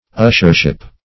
Ushership \Ush"er*ship\, n. The office of an usher; usherdom.